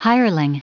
Prononciation du mot : hireling